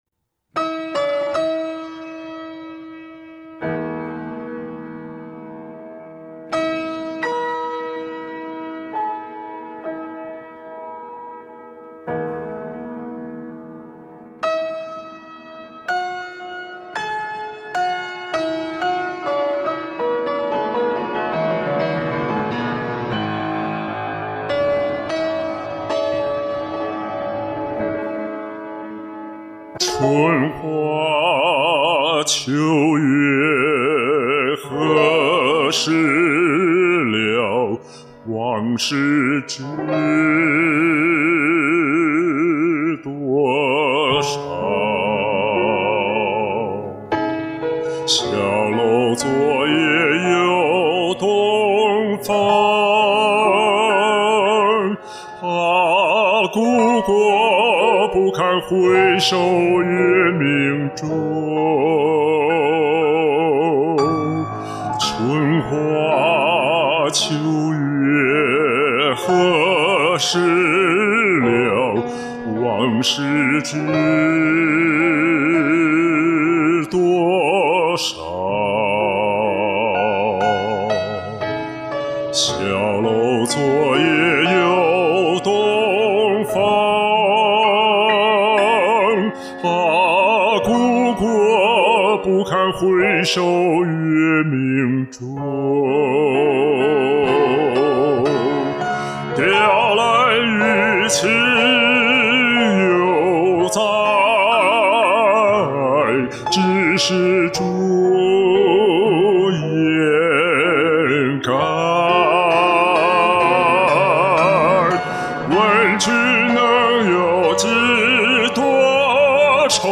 醇厚的嗓音令人深深陶醉
醇厚宽广沉郁的美声
深沉醇厚的嗓音，丰富的情感表达把这首歌表达淋漓尽致。
浑厚醇美的音色，收放自如的演唱。